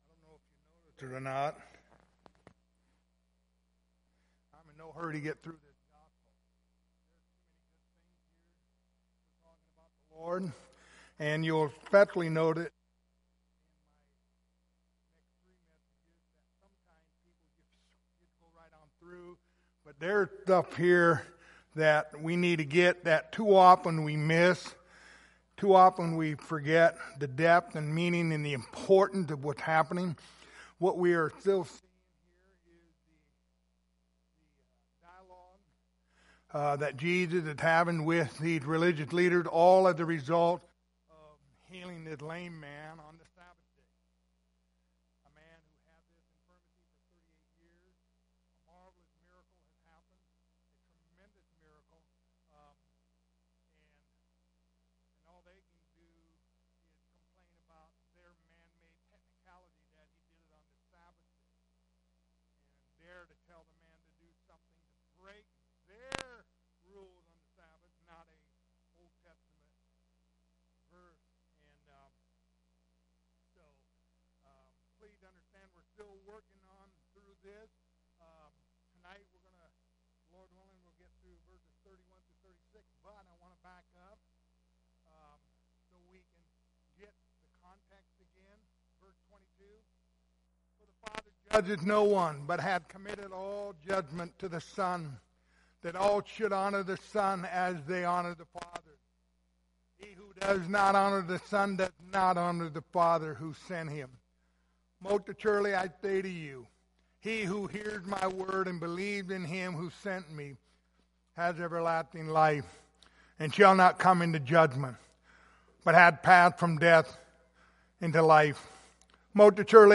Passage: John 5:31-39 Service Type: Wednesday Evening